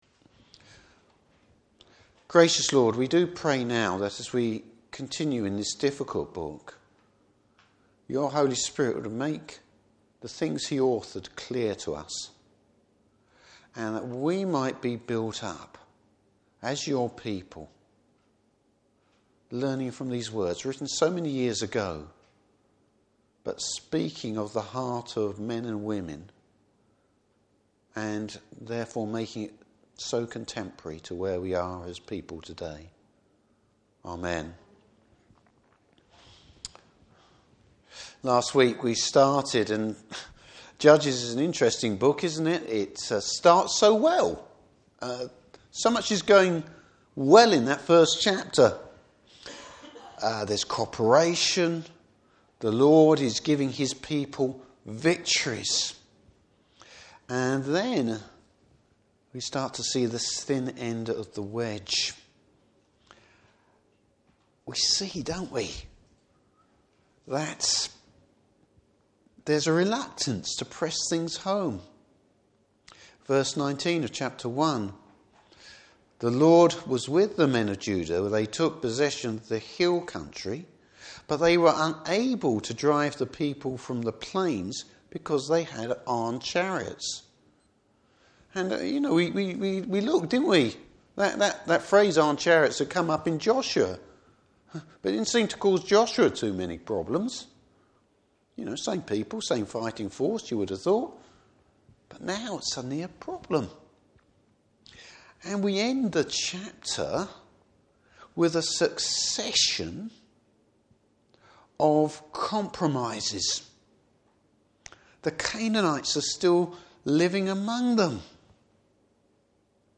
Service Type: Evening Service Bible Text: Judges 2:1-3:6.